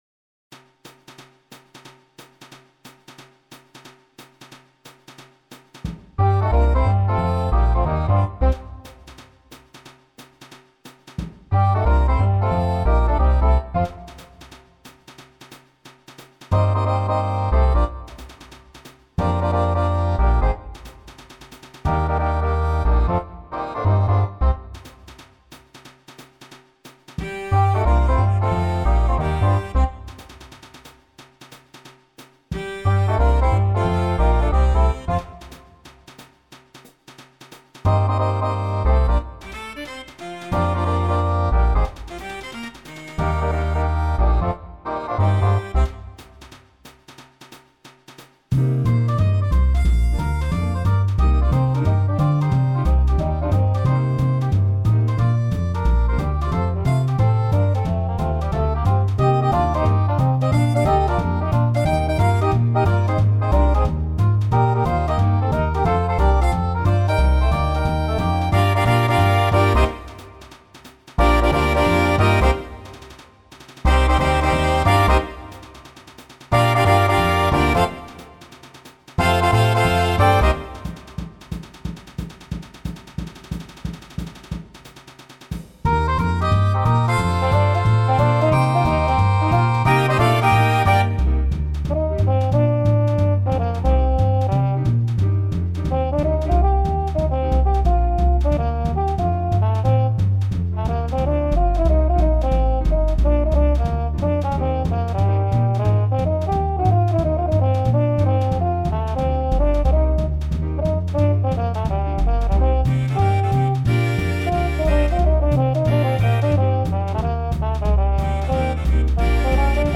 This page lists many of my original charts (compositions and arrangements) for big band.
In these cases, I generated MIDI-based recordings using Dorico and some nice sample libraries. I then used an audio editor to add solos (which I played from a keyboard) and piano comping as needed.